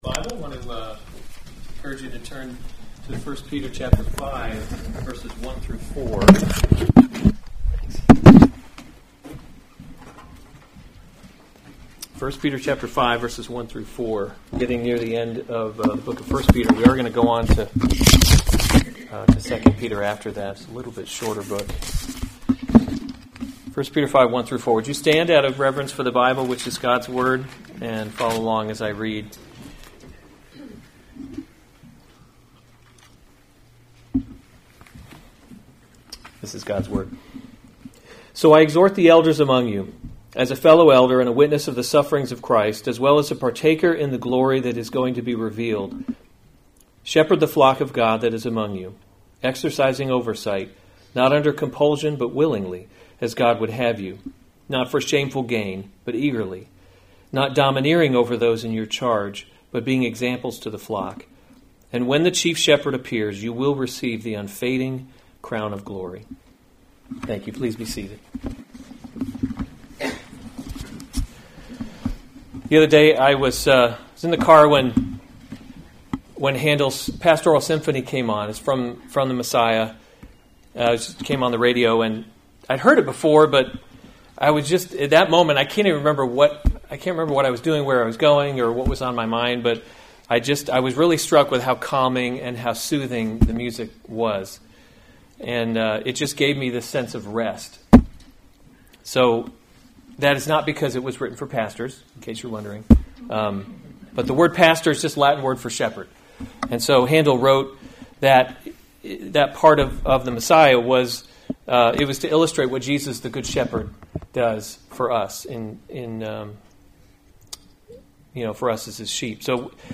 February 8, 2020 1 Peter – Covenant Living series Weekly Sunday Service Save/Download this sermon 1 Peter 5:1-4 Other sermons from 1 Peter Shepherd the Flock of God 5:1 So I […]